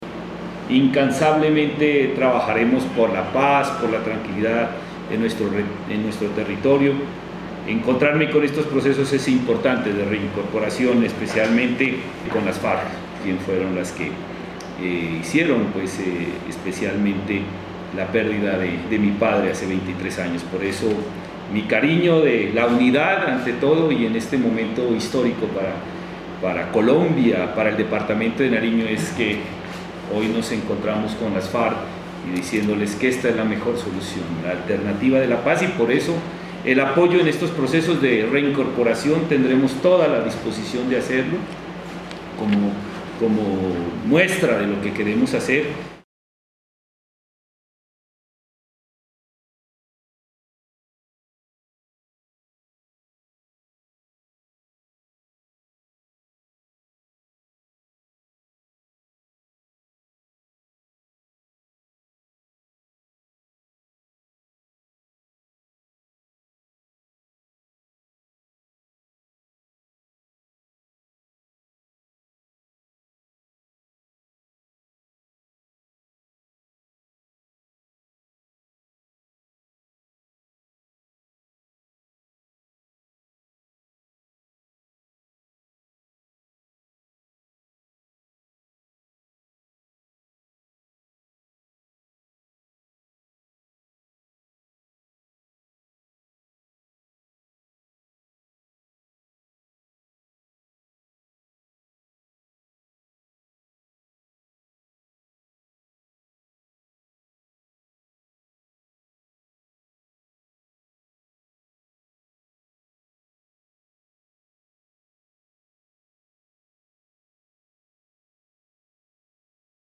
El Gobernador de Nariño, Jhon Rojas, manifestó que alcanzar la paz debe ser el propósito de todos.